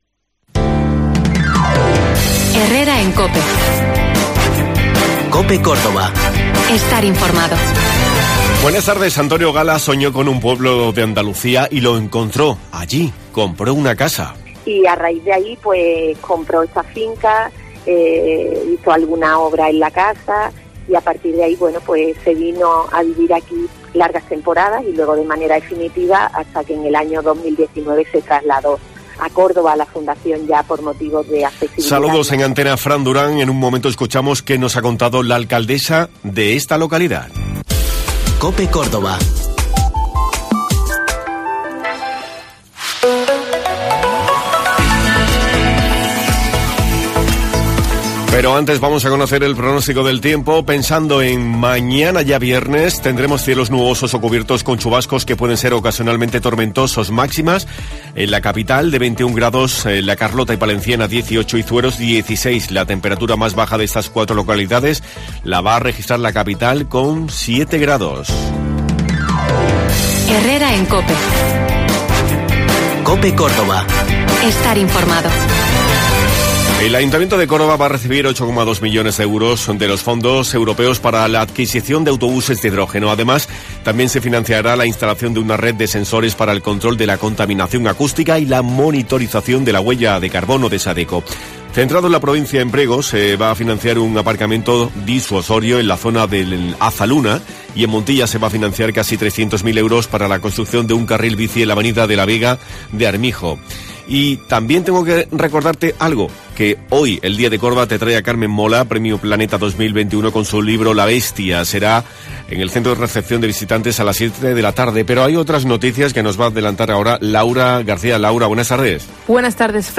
Hoy hemos hablado con la alcaldesa del municipio donde el escritor pasó varios veranos y donde fue lugar de inspiración para muchas de sus obras.